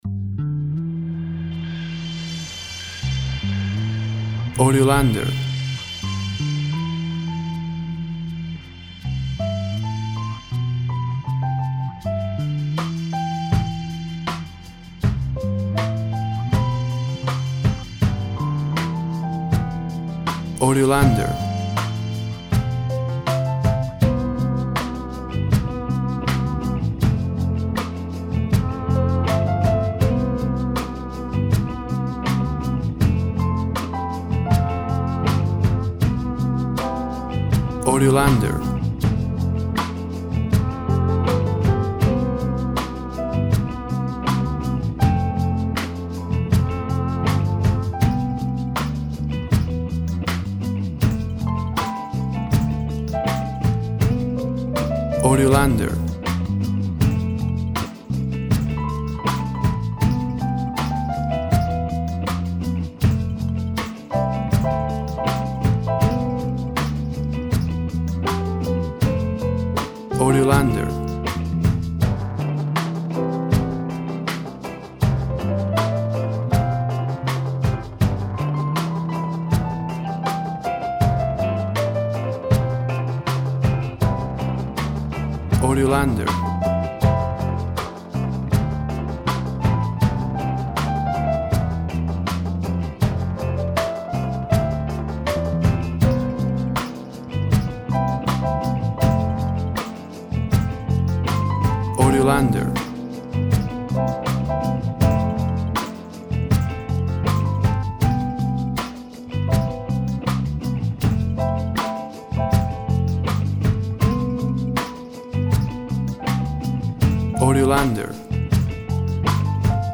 Happy and fun music.
WAV Sample Rate 24-Bit Stereo, 44.1 kHz
Tempo (BPM) 80